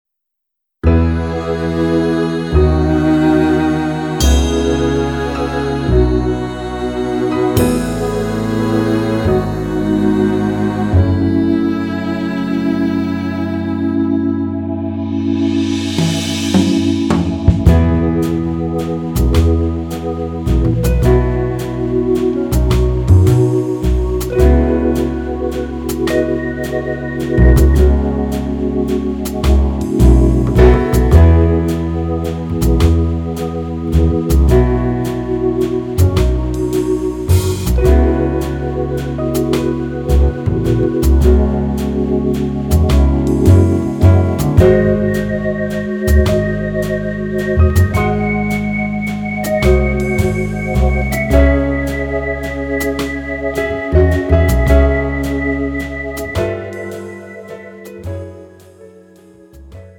장르 pop 구분 Pro MR